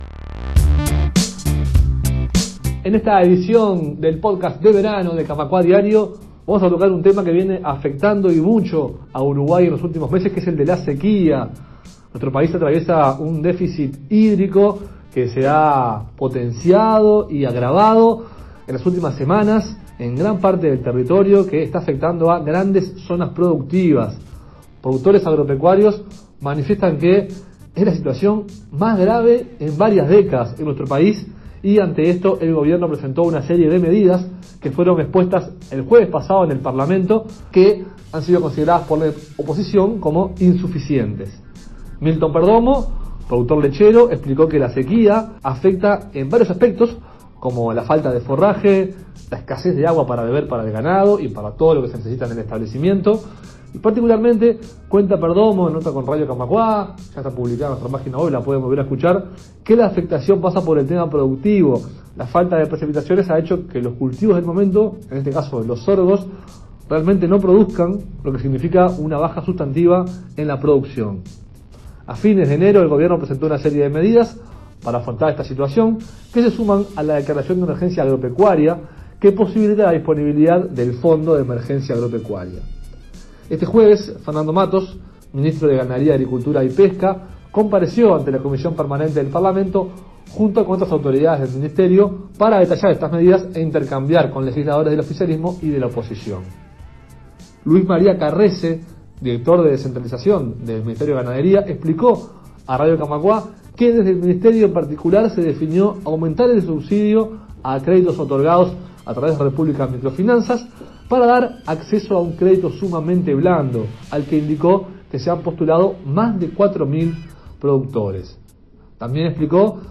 Conversamos con Luis María Carresse, director de Descentralización del Ministerio de Ganadería, Agricultura y Pesca; y con el senador frenteamplista Sebastián Sabini.